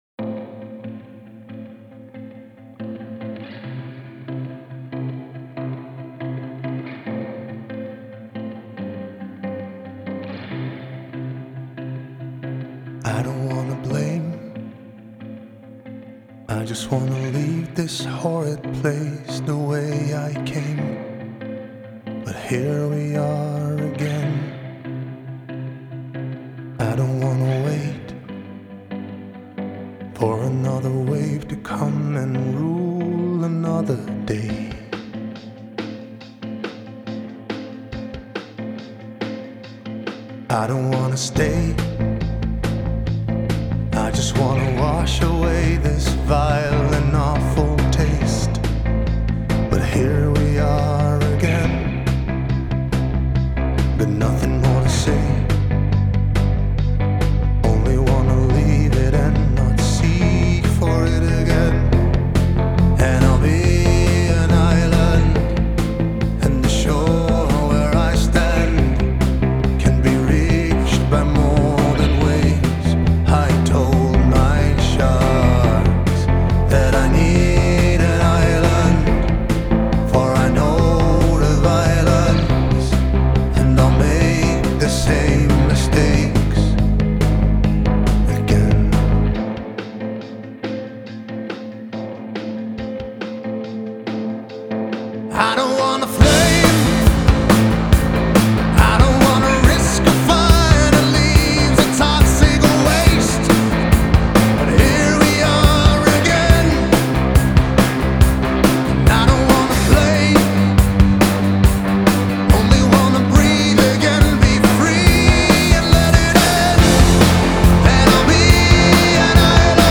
Genre: Alternative / Indie Rock